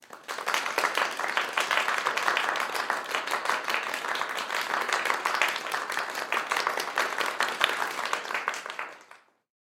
Звуки ликования
Аплодисменты пятнадцати человек — второй вариант